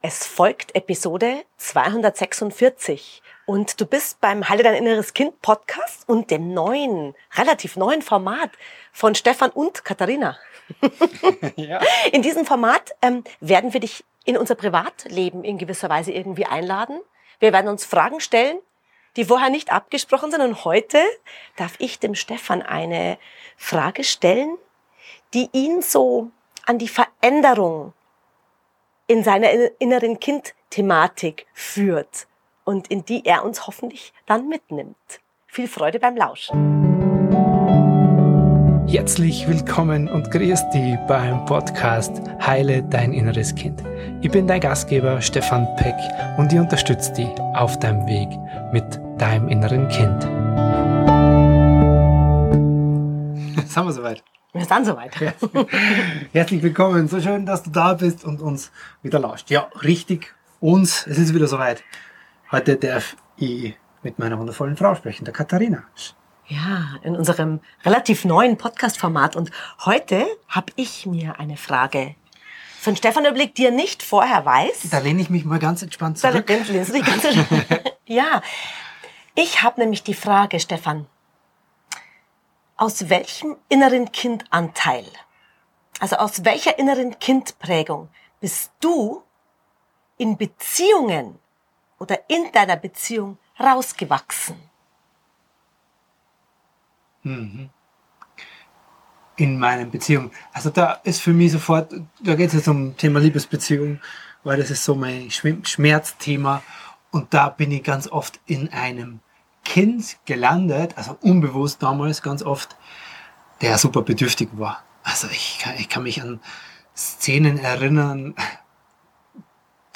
Es ist Teil unseres neuen Formates, bei dem wir uns gegenseitig unbequeme, ehrliche Fragen stellen – unvorbereitet, offen und direkt aus dem Leben.